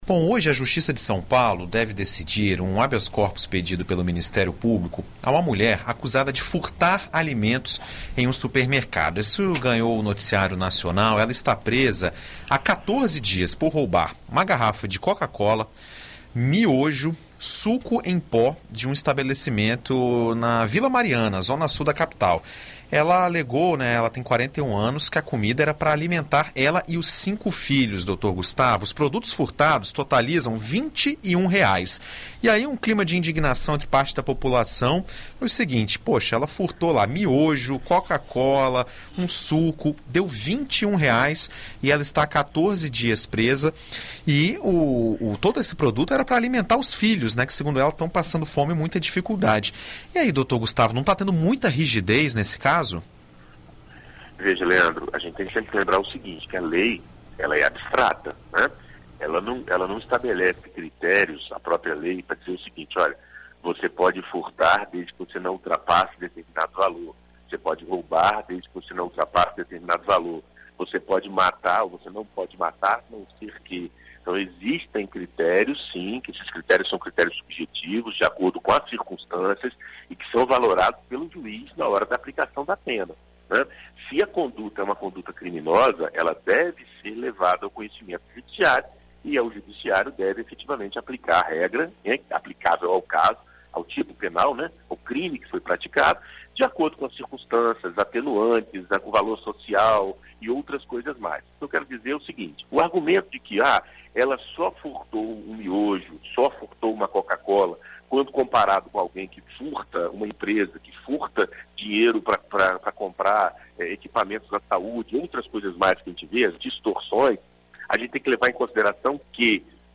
na BandNews FM Espírito Santo